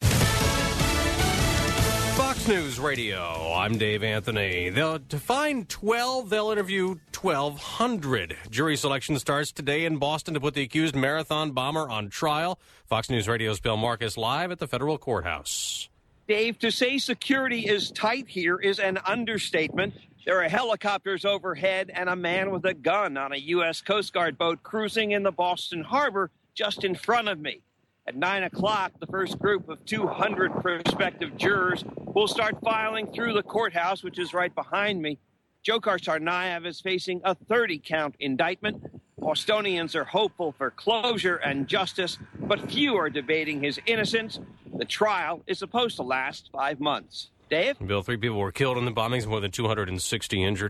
8AM Live…
8am-live.mp3